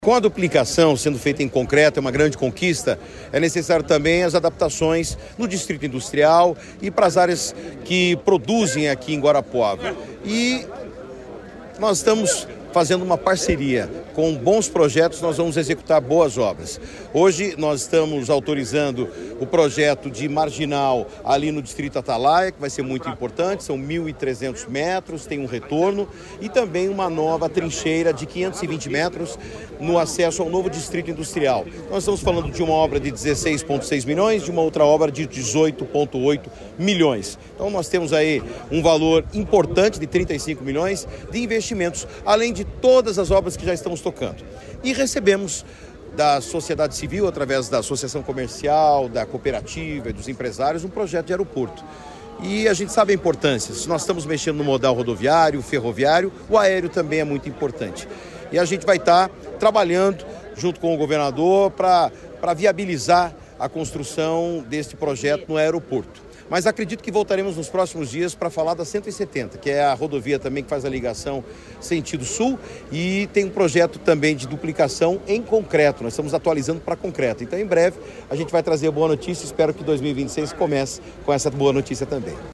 Sonora do secretário de Infraestrutura e Logística, Sandro Alex, sobre as novas obras de infraestrutura para o polo industrial de Guarapuava